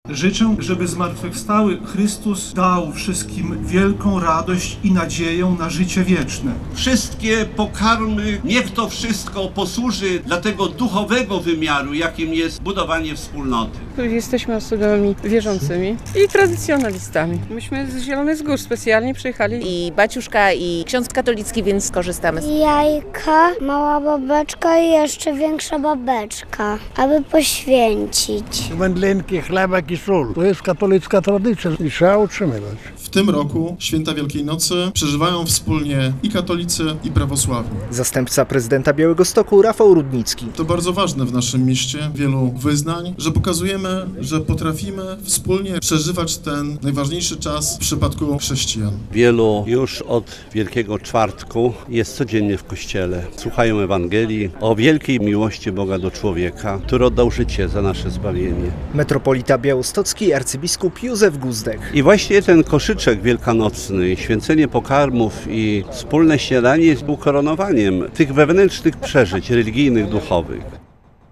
Ekumeniczne święcenie pokarmów w centrum Białegostoku